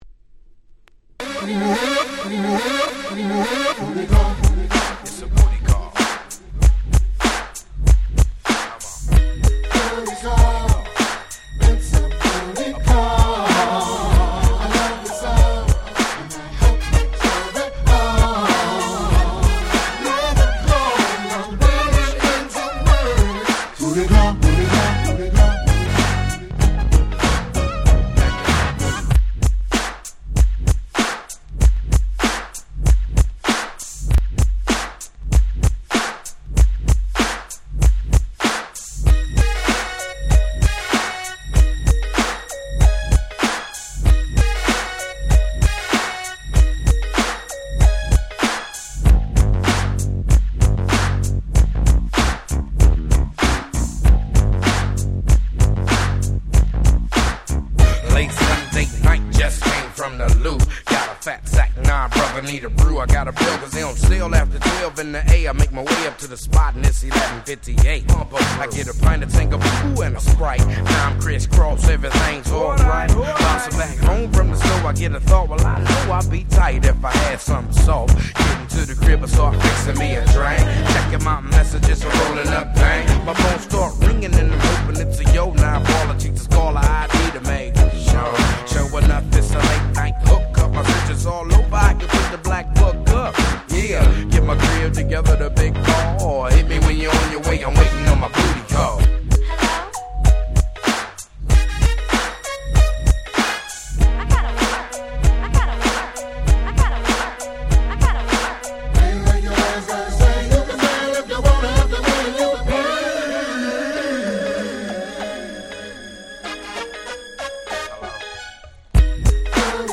94' Smash Hit West Coast Hip Hop !!
90's G-Rap ギャングスタラップ ウエストコースト ウエッサイ